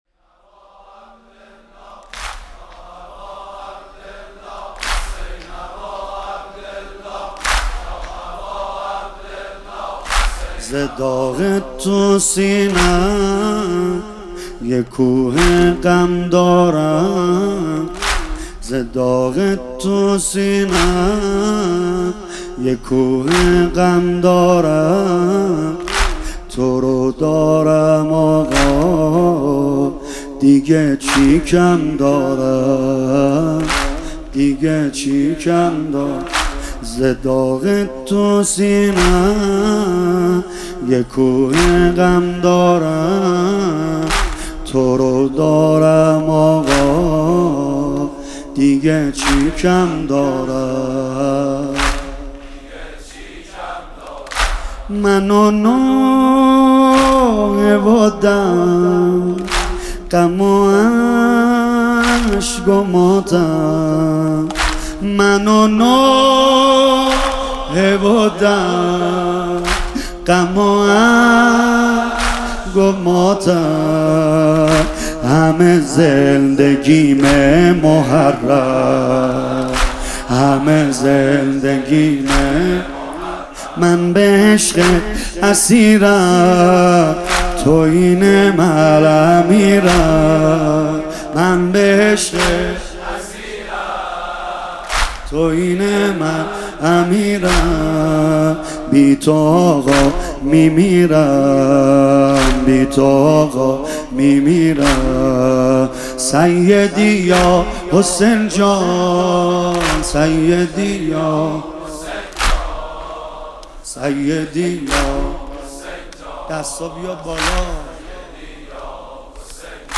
سیدرضانریمانی